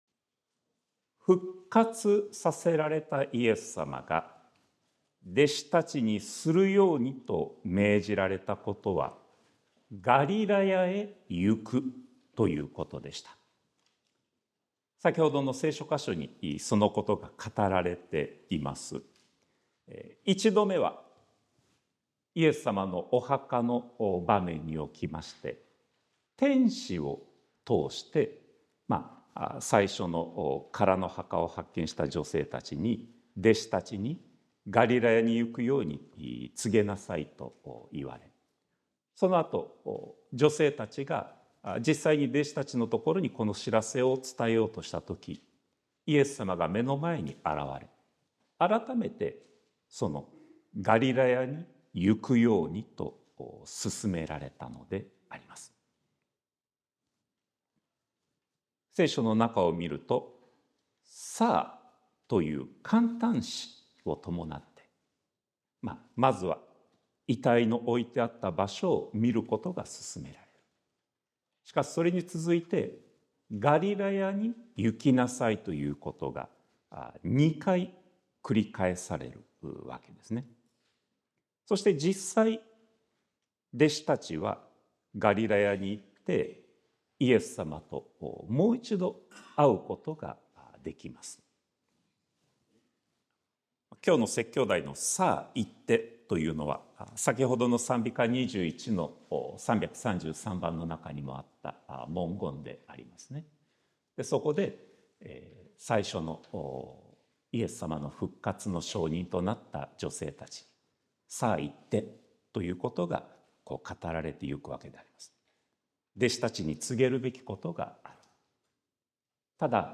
sermon-2025-04-20